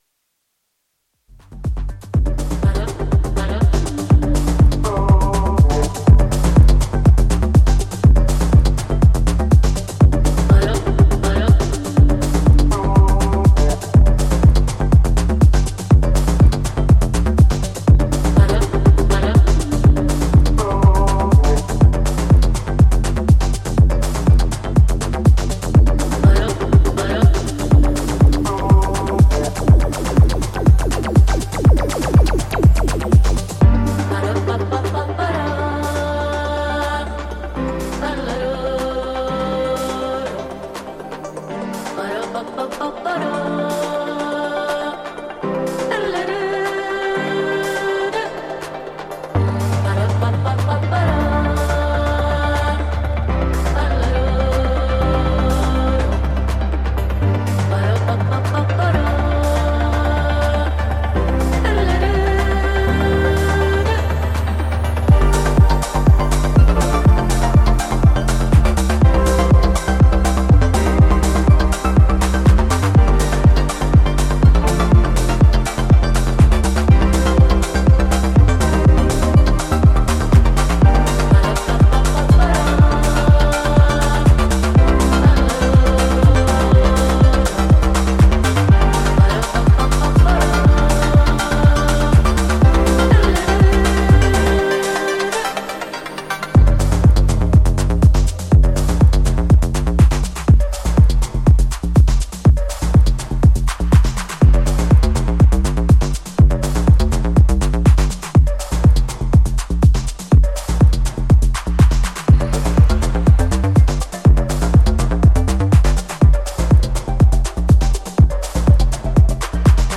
ディスコ/テック/バレアリックといったフロア受け抜群のハウスを展開するダンス・トラック全4曲を収録。
ジャンル(スタイル) HOUSE / DISCO HOUSE